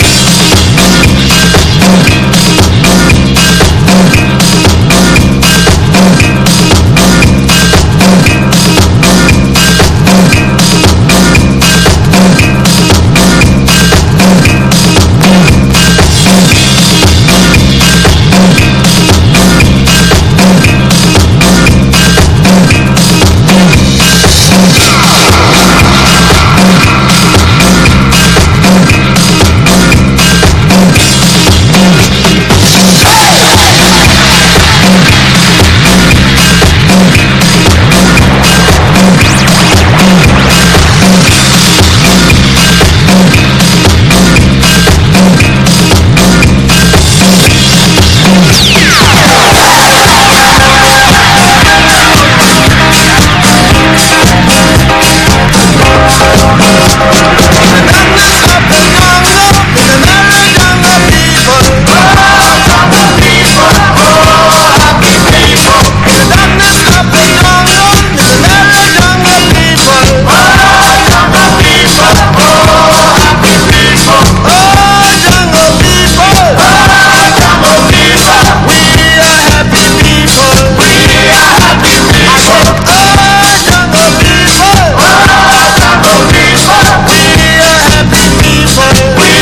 原曲を倍尺近くまで引き延ばしたフロア・フレンドリーな強力エディットを渾身のワンサイド・プレスにて搭載!!